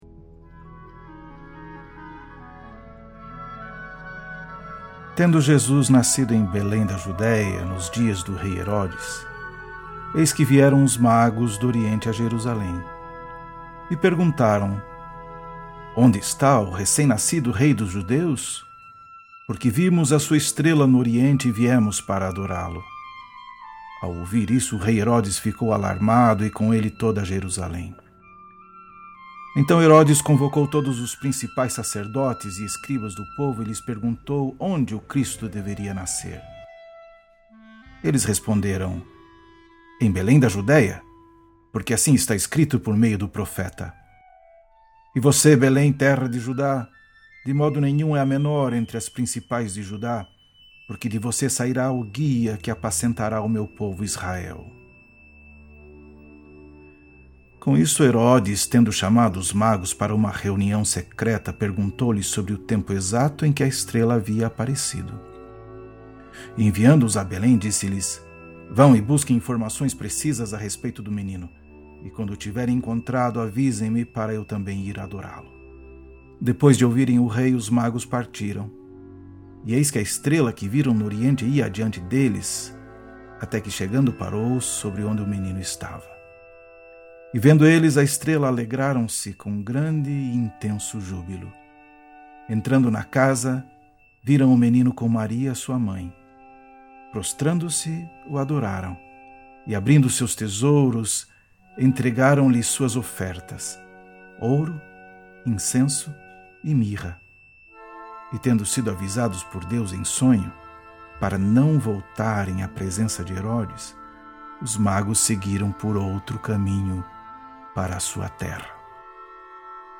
Trilha sonora: Scheherazade, Part IV – Nikolay Rimsky KorsakoffArr. – parte final